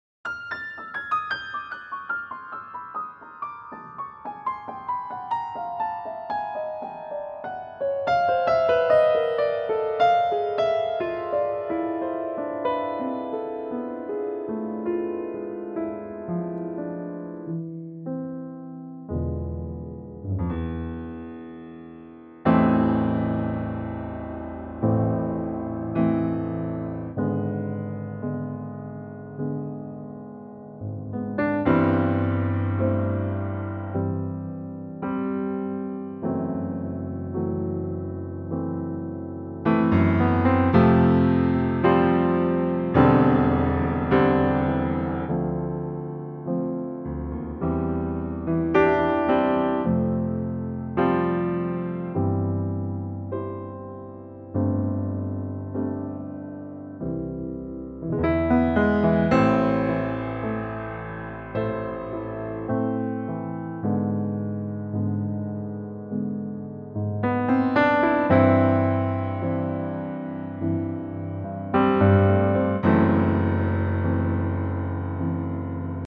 Unique Backing Tracks
Piano intro and vocal in at 24 seconds
Suitable for typical male ranges.